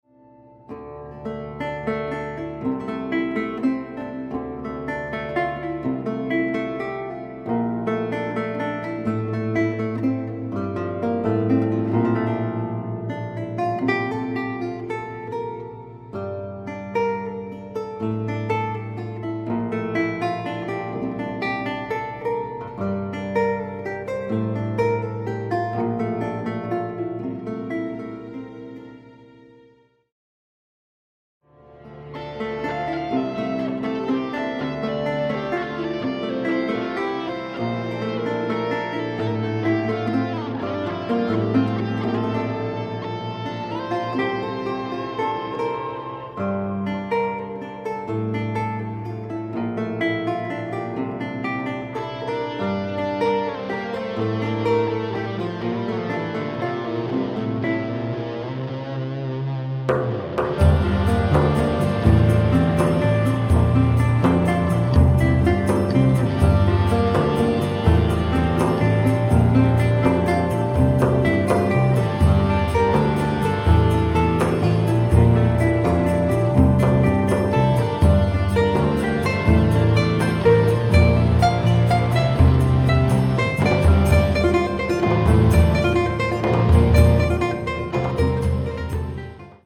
composer, lute & oud player from Japan
Contemporary , Lute
Progressive Metal